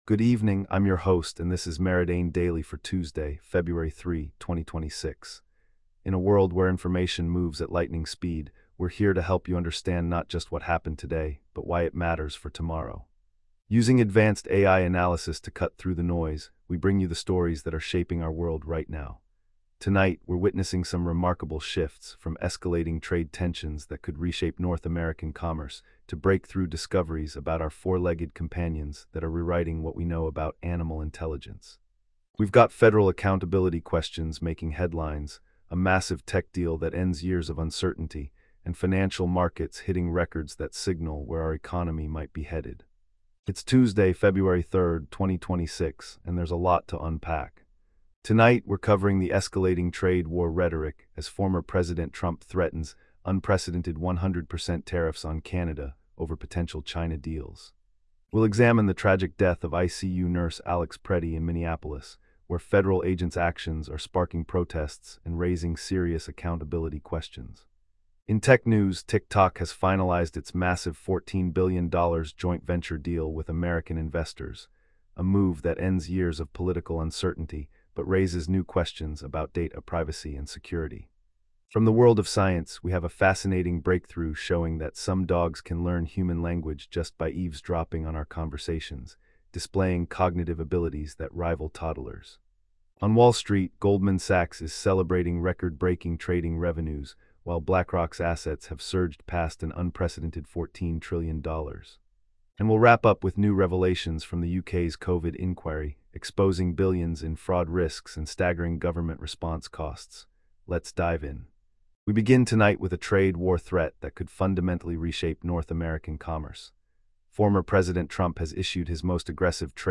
Your nightly AI-powered news briefing for Feb 3, 2026